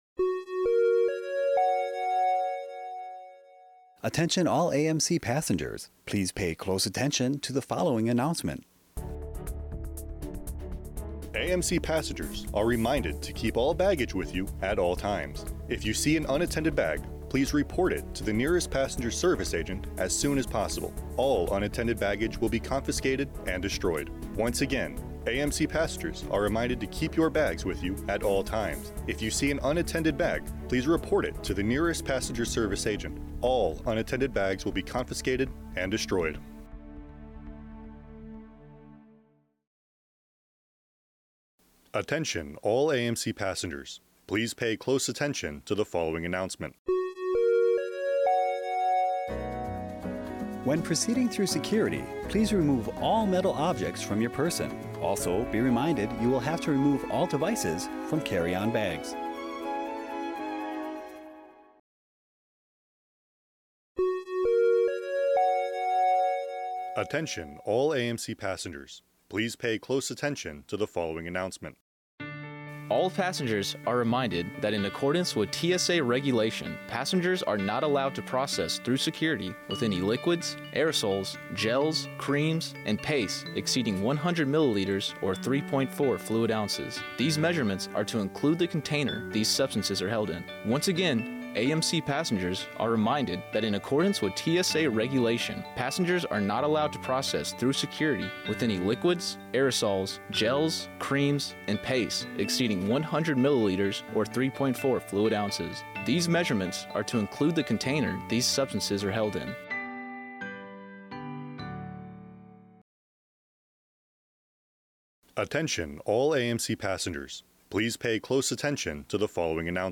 This readout contains messaging about TSA Regulations, securing your luggage, protecting your pets and tips for moving through security efficiently, narrated by service members assigned to Media Bureau Japan.